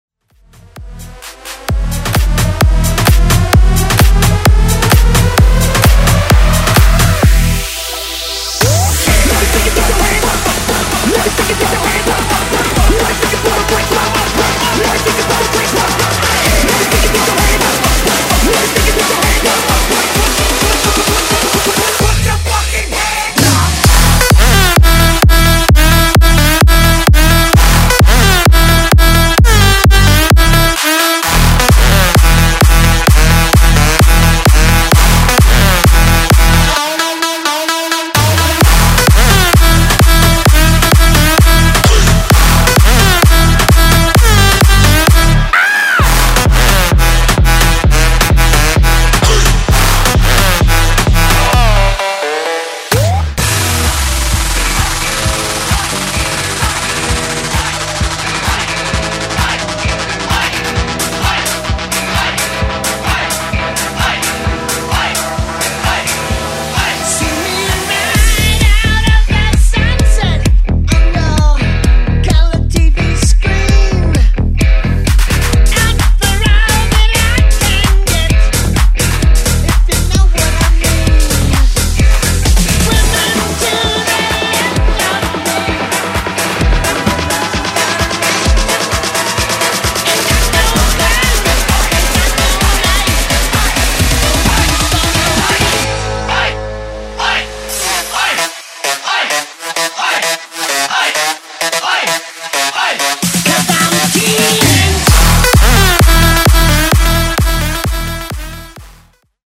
Genre: MASHUPS
Clean BPM: 110 Time